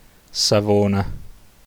Savona (Italian: [saˈvoːna]
It-Savona.ogg.mp3